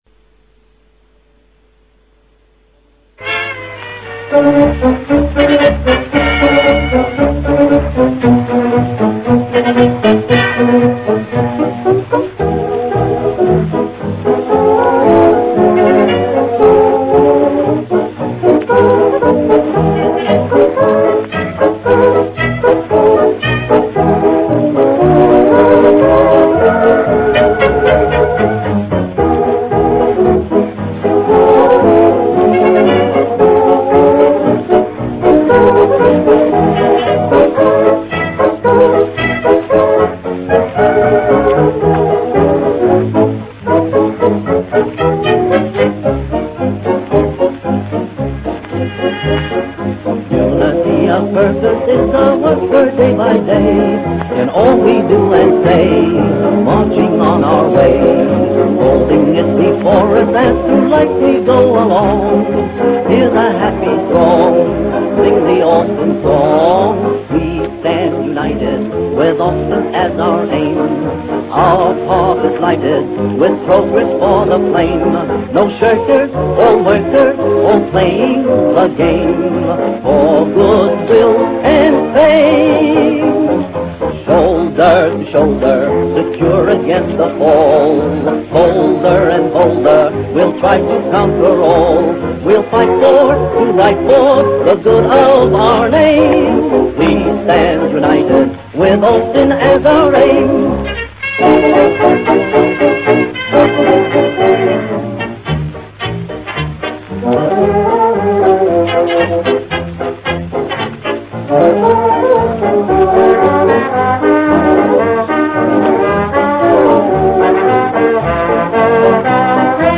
Early Austin Seven Audio Recordings